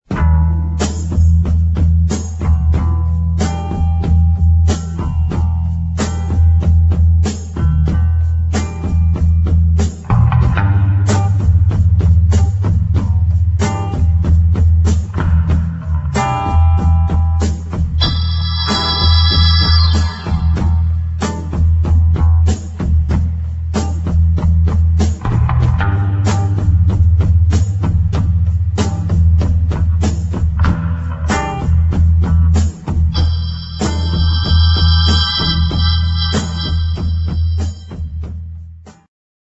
funny fast instr.